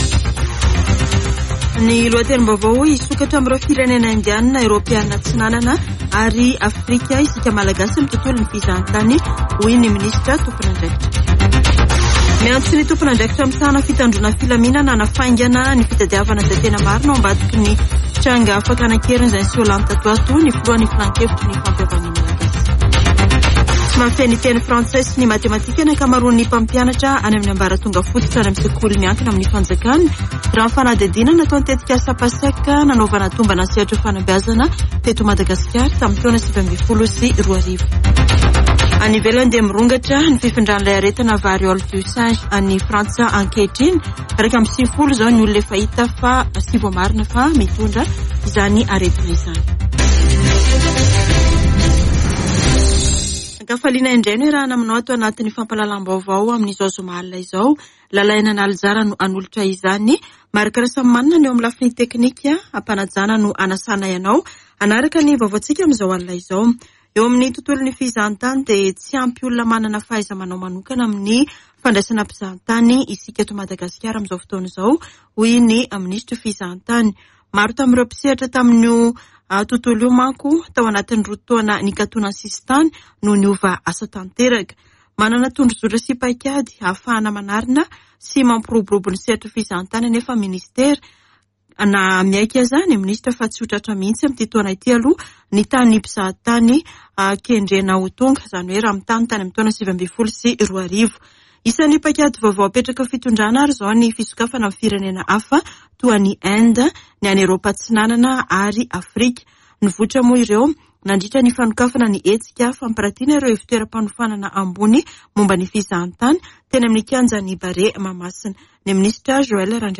[Vaovao hariva] Zoma 10 jona 2022